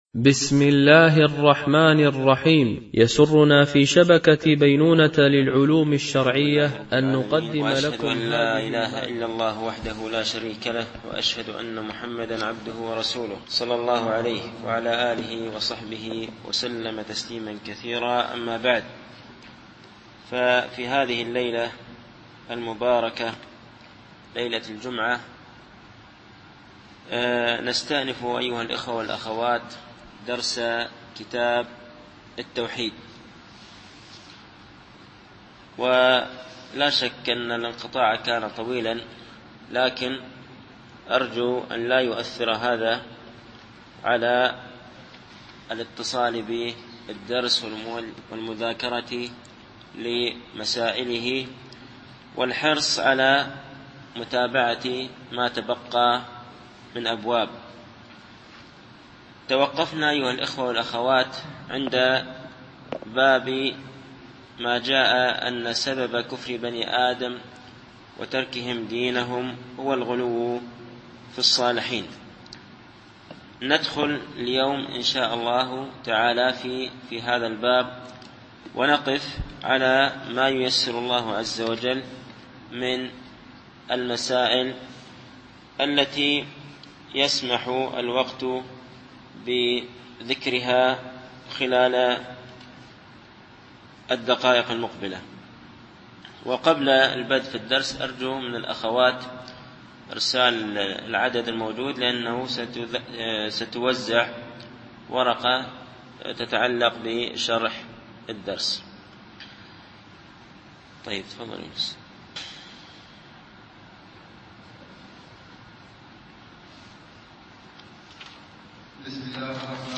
الدرس الحادي و الخمسون